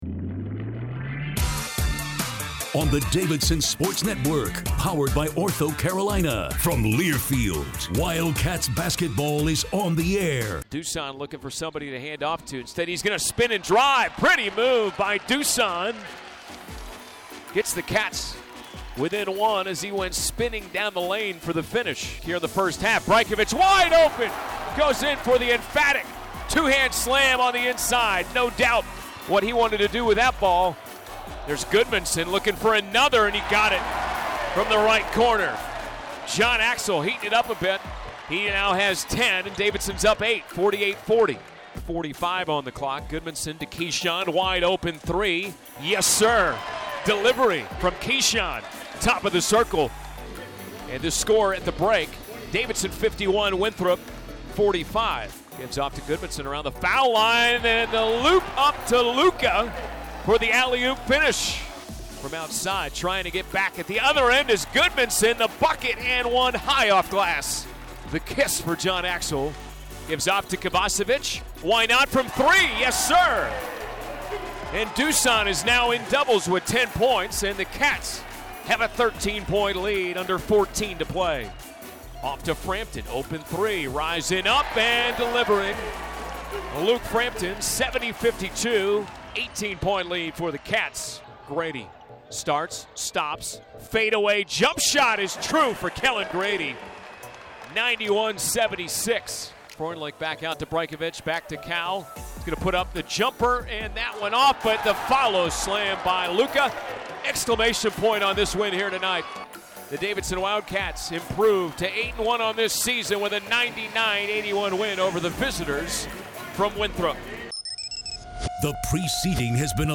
Radio Highlights
WinthropHighlights.mp3